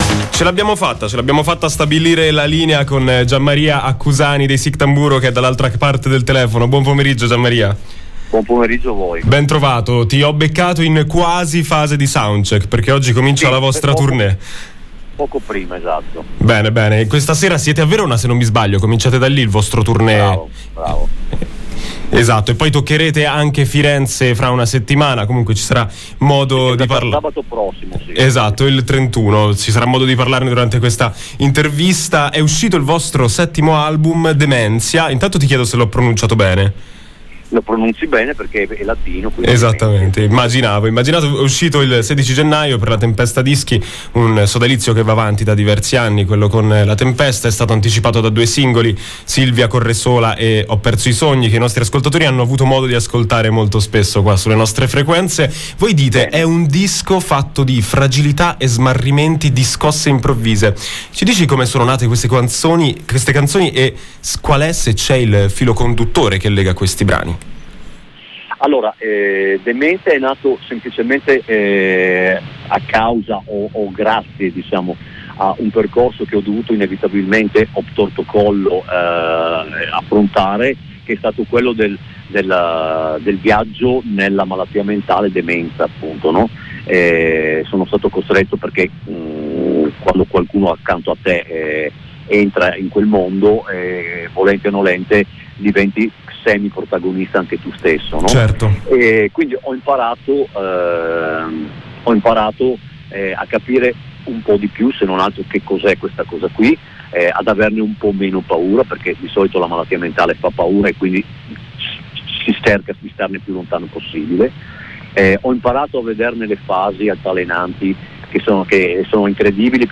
MusicaConcertiIntervisteOspiti in studio